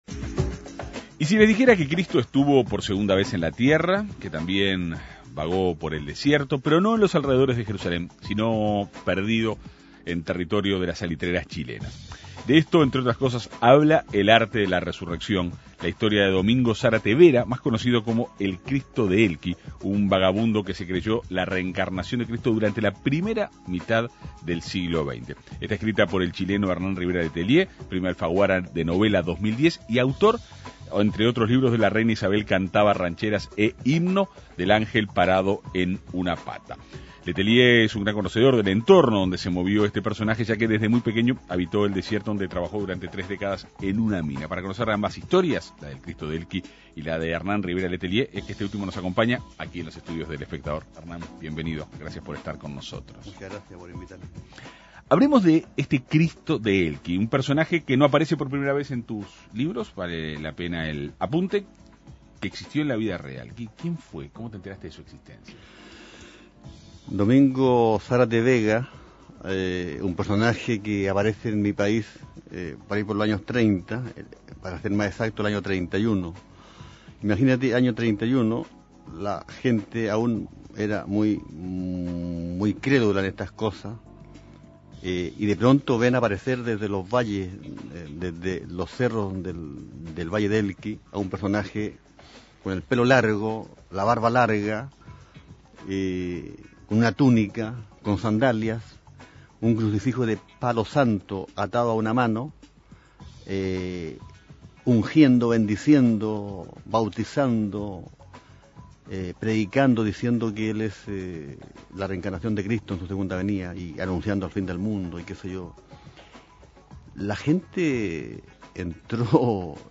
El autor dialogó en la Segunda Mañana de En Perspectiva.